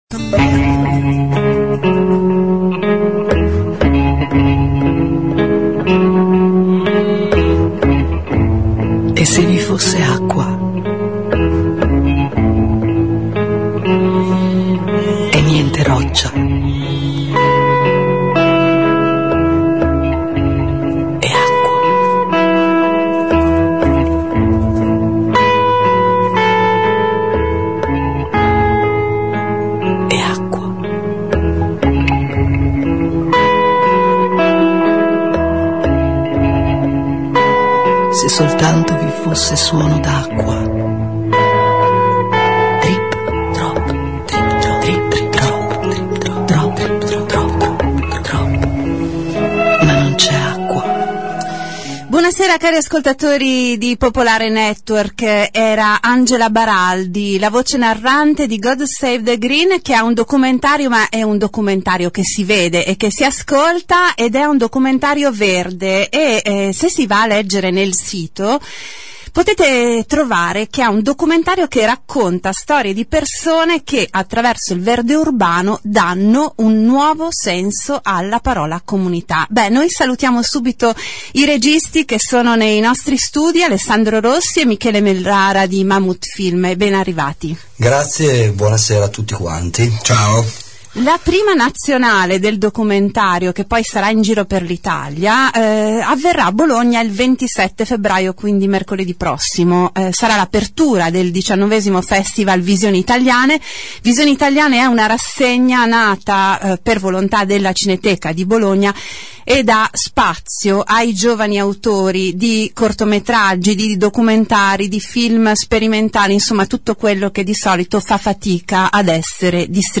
Ecco la trasmissione in cui Radio Città del Capo l’ha presentato